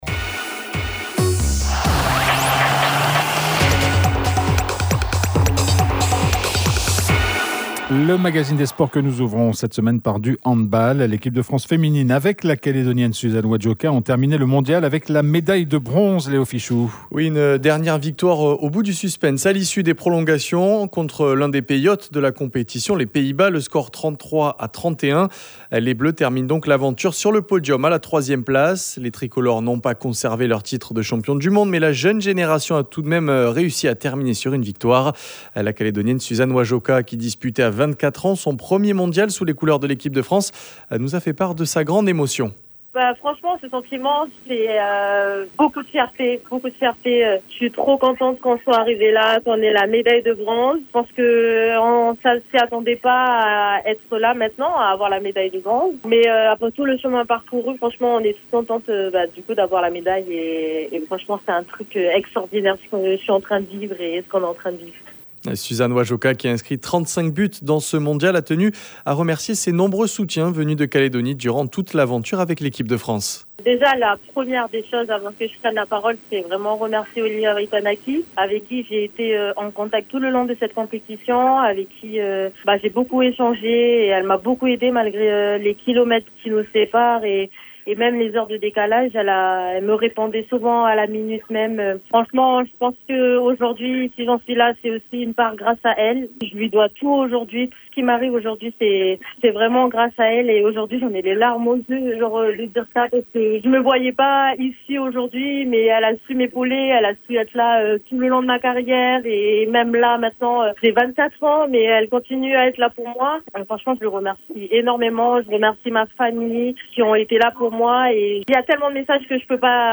Il sera dans nos studios, à midi.